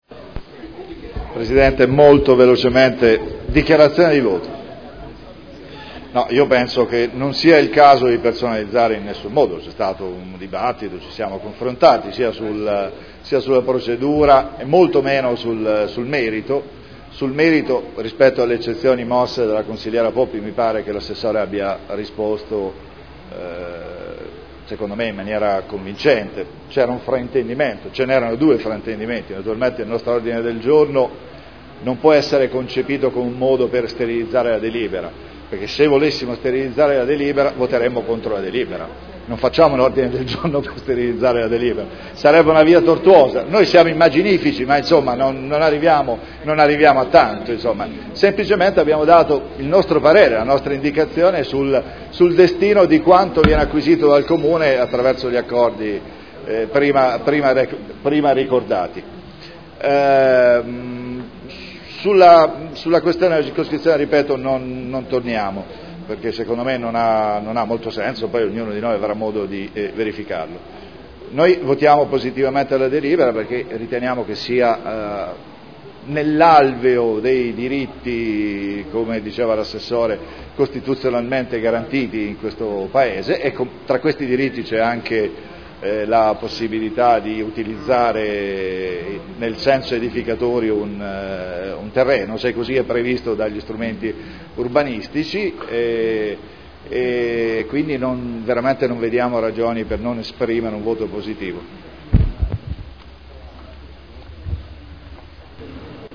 Seduta del 24/09/2012 Dichiarazione di voto su Delibera Zona elementare 280 Area 01 e Area 06 – Via Giardini – Variante al POC-RUE con valore ed effetti di Piano Urbanistico Attuativo (PUA) e Mozione prot. 111618